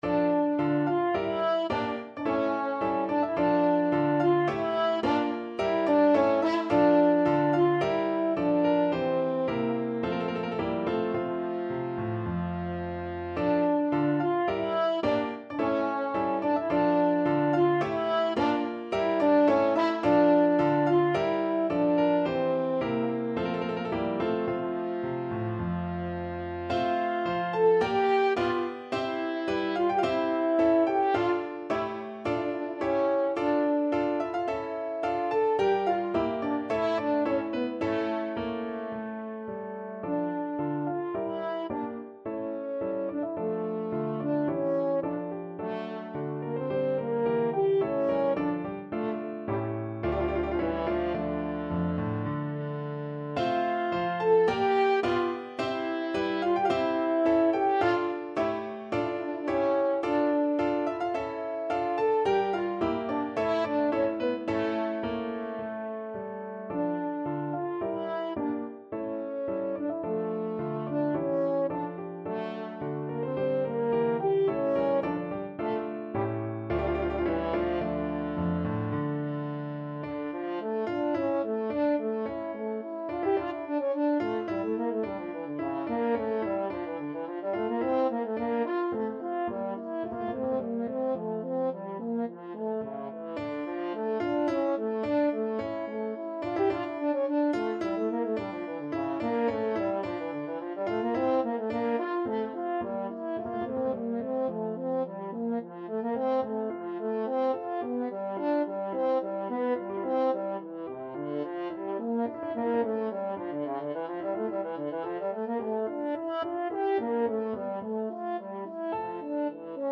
French Horn
D minor (Sounding Pitch) A minor (French Horn in F) (View more D minor Music for French Horn )
3/4 (View more 3/4 Music)
~ = 54 Moderato
Classical (View more Classical French Horn Music)